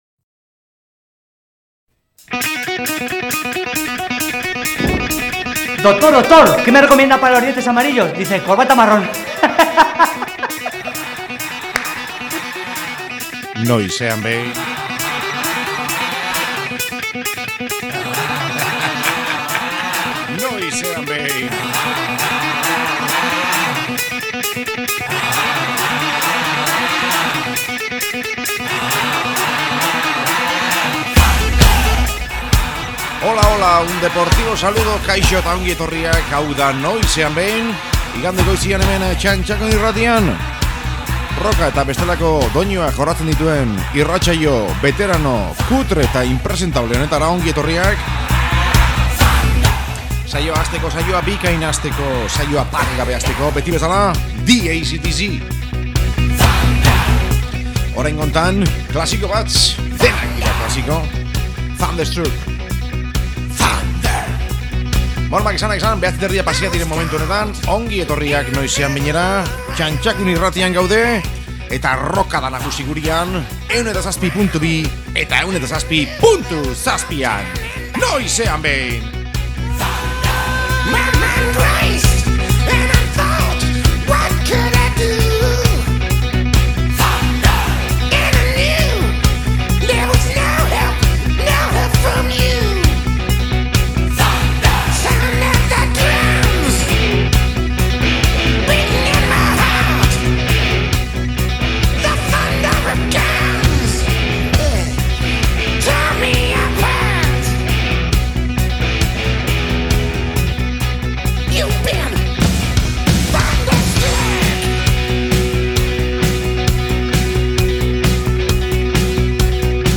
Blues, jebi, hardcore eta txorrada mordo bat.